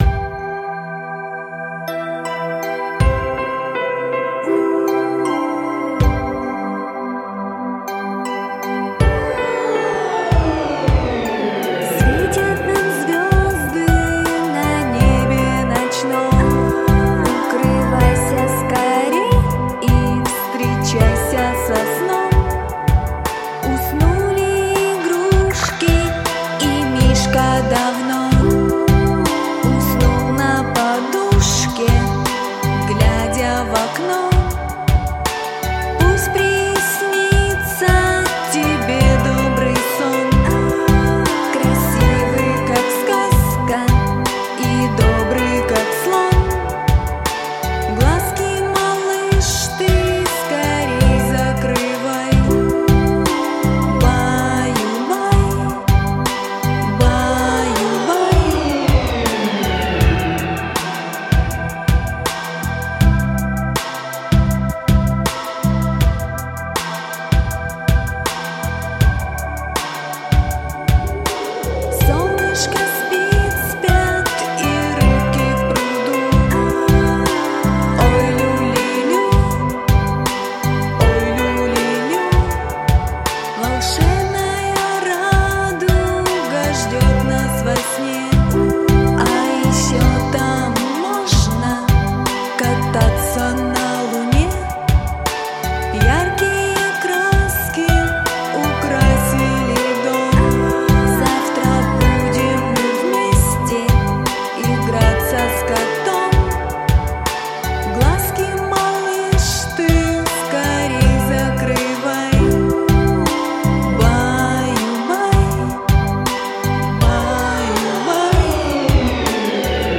• Качество: Хорошее
• Жанр: Детские песни
малышковые